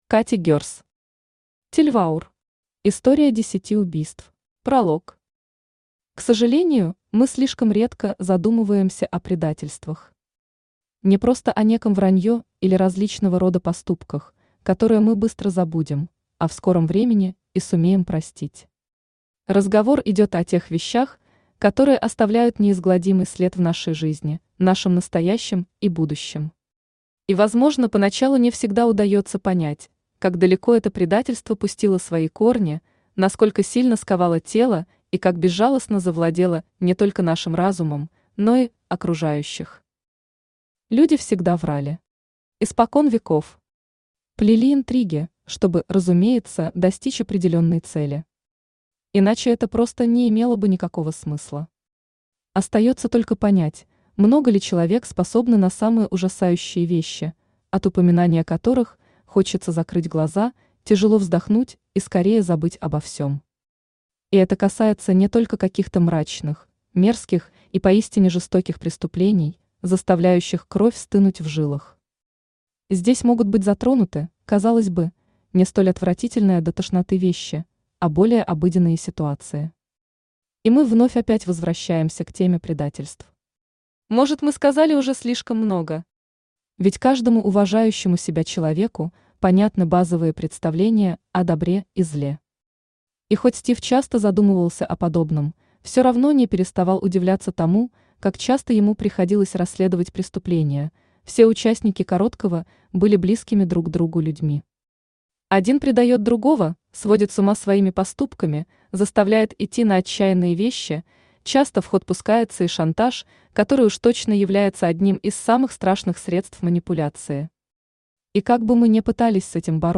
Аудиокнига Тельваур. История десяти убийств | Библиотека аудиокниг
История десяти убийств Автор Катя Герс Читает аудиокнигу Авточтец ЛитРес.